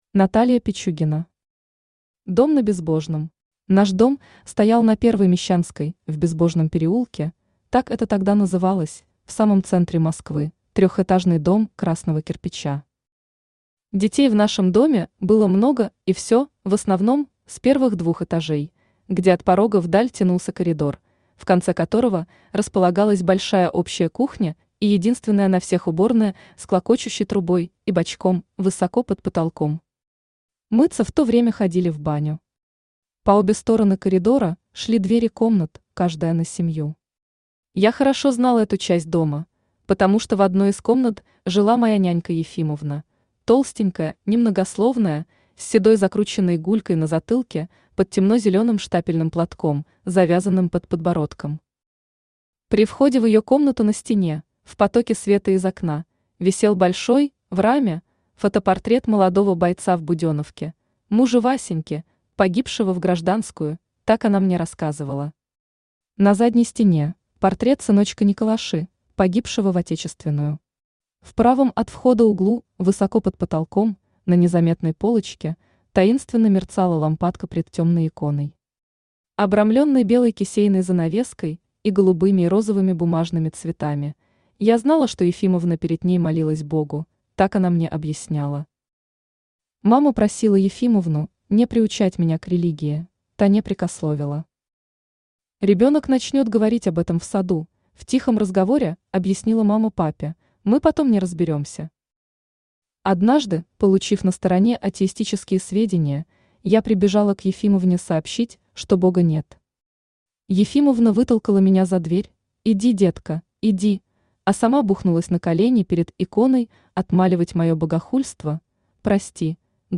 Аудиокнига Дом на Безбожном | Библиотека аудиокниг
Aудиокнига Дом на Безбожном Автор Наталья Пичугина Читает аудиокнигу Авточтец ЛитРес.